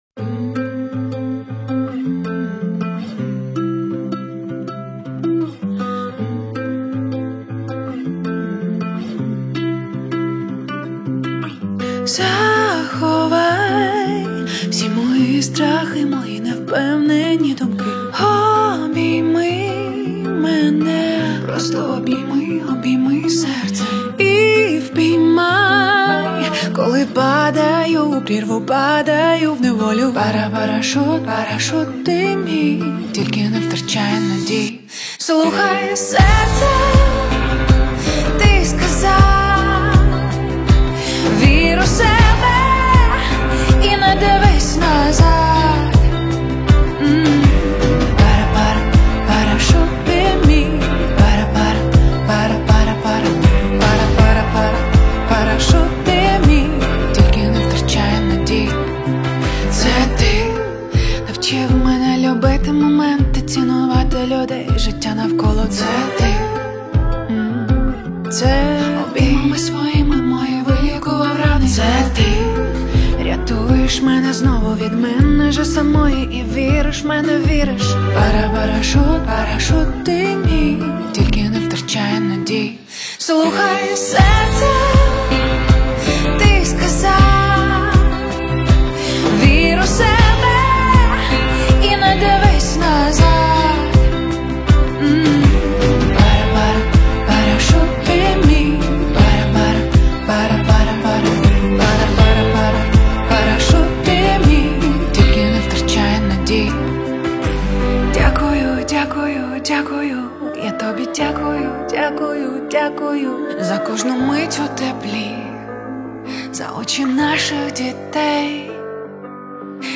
• Жанр:Поп